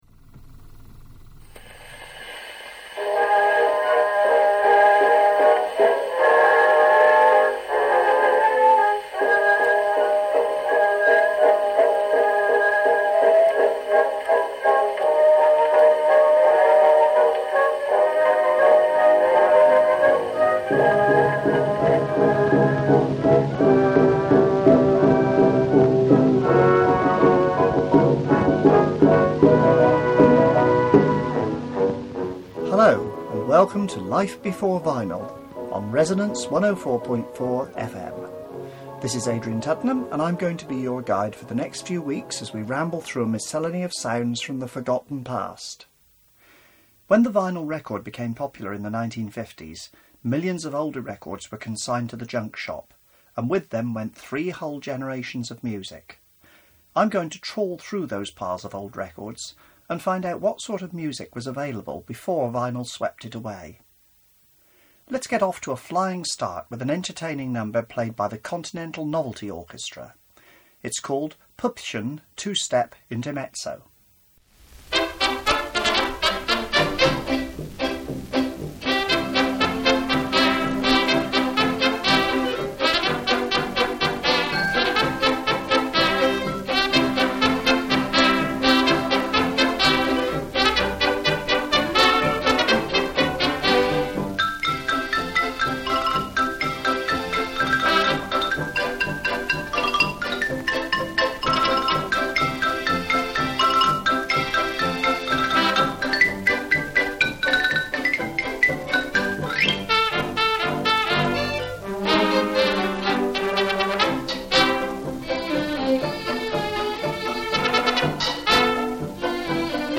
'Clocketting', the sound of a faulty recording lathe.